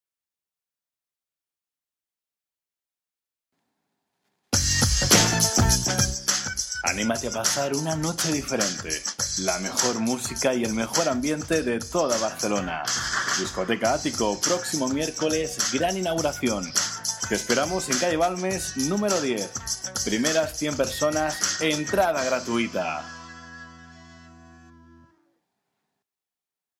Spanish professional announcer
kastilisch
Sprechprobe: Werbung (Muttersprache):